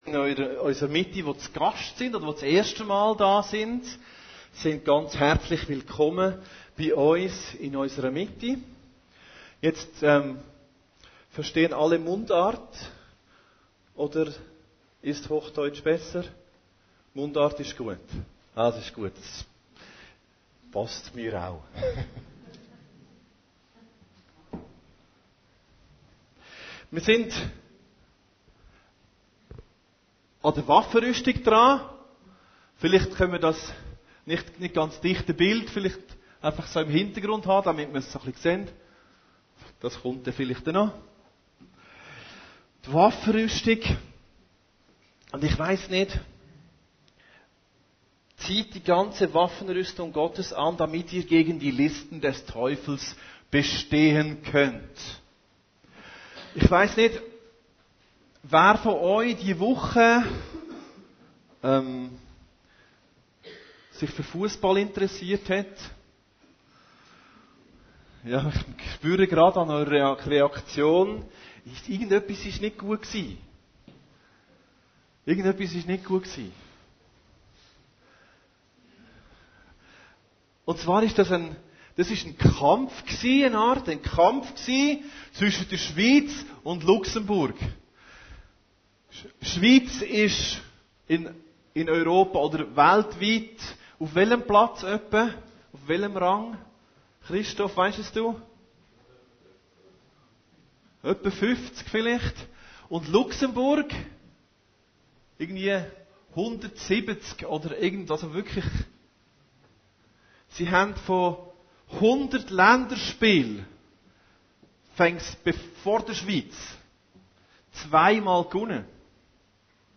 Predigten Heilsarmee Aargau Süd – Waffenrüstung Gottes - Teil 2